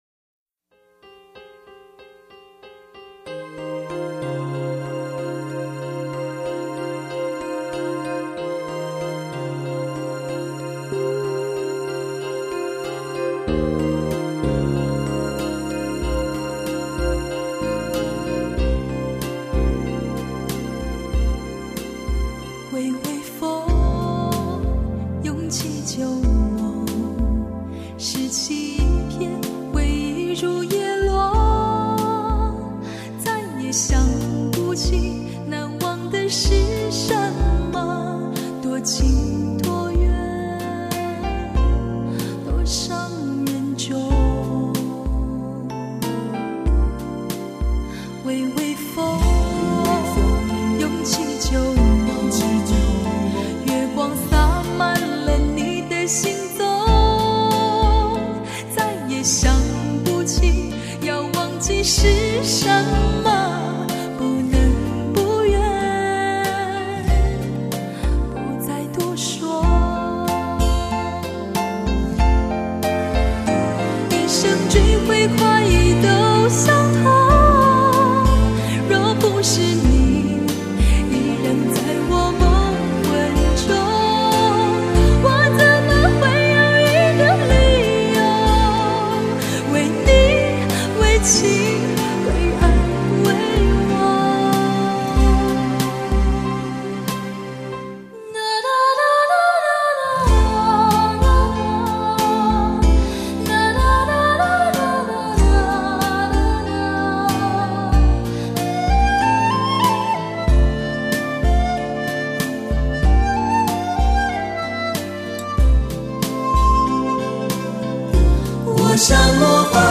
特别精选２ＣＤ爱情男女情歌对话